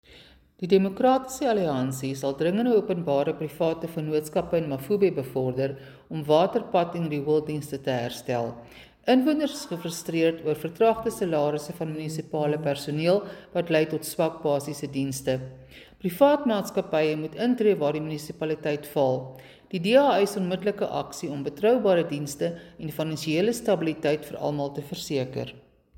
Afrikaans soundbites by Cllr Suzette Steyn and